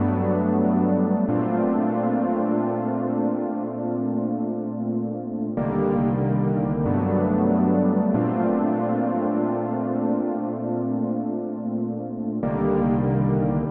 Cloud Chords 140 bpm.wav